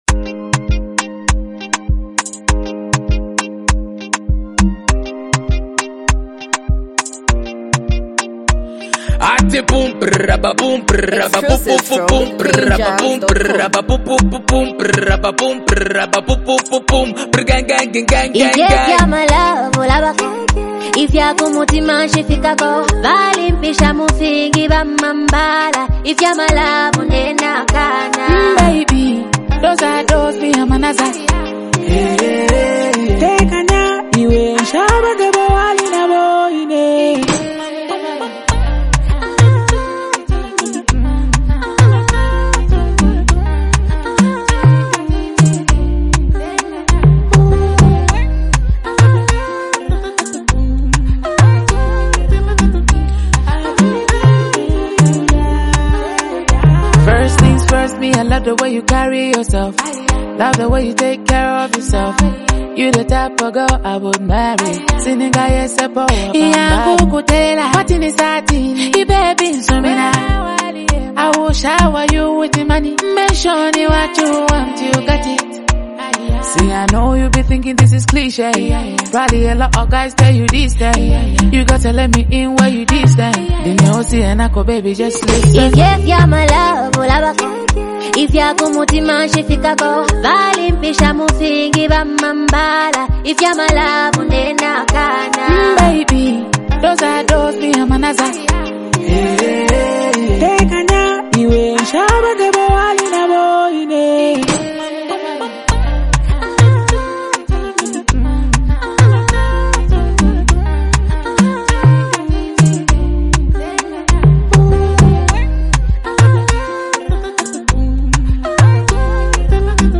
is a smooth and emotionally rich song
love anthem